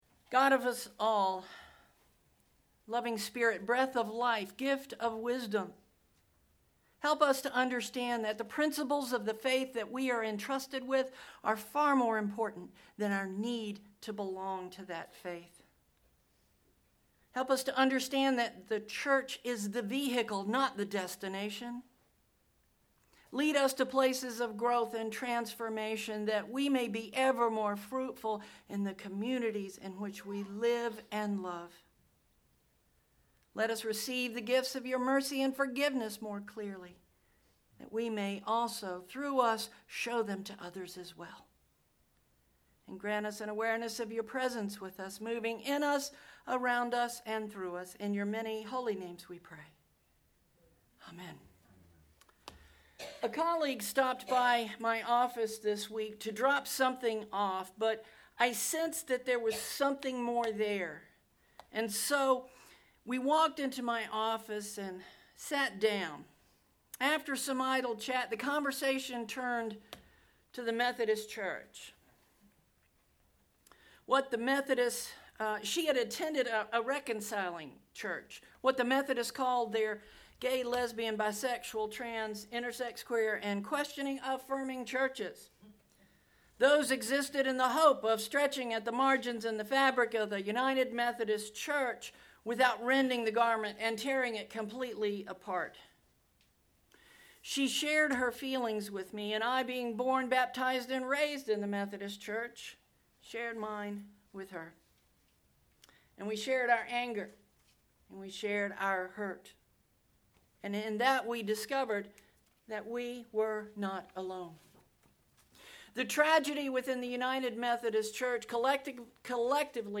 Click below to listen to the sermon from Sunday 03/24/19